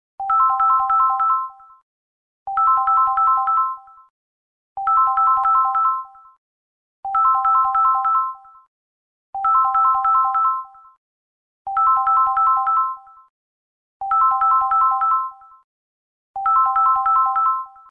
Atomic bell 🔔 clips: sound effects free download